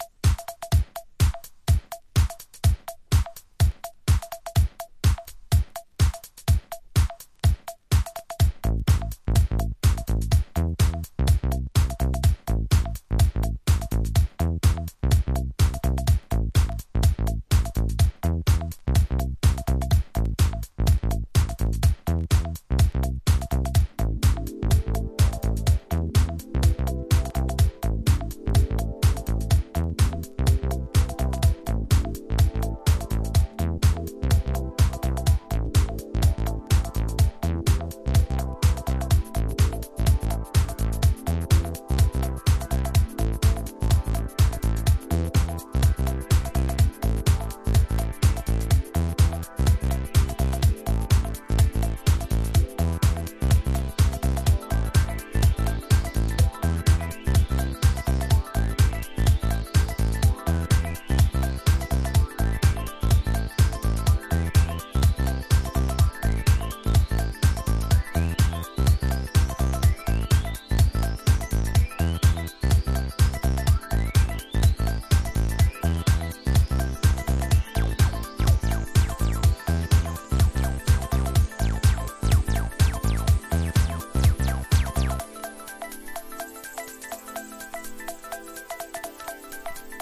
コズミック・エレクトロ・ディスコ！